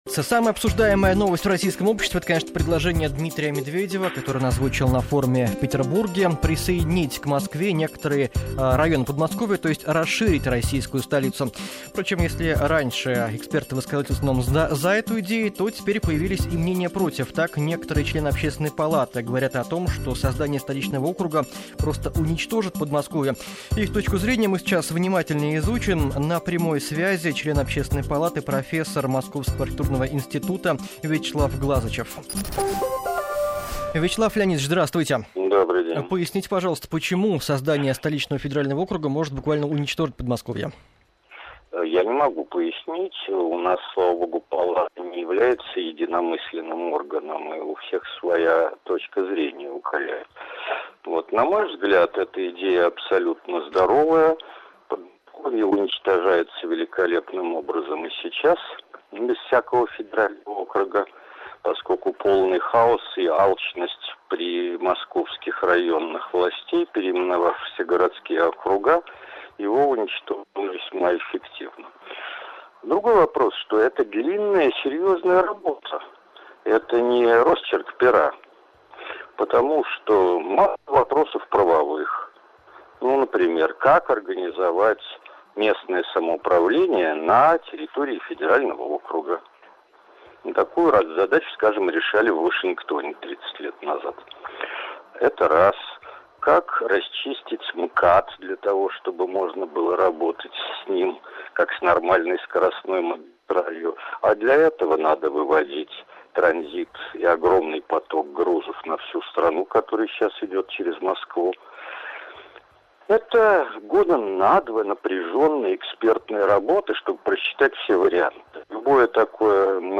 Телефонное интервью для «Вести.ФМ», 20.06.2011